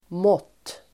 Uttal: [måt:]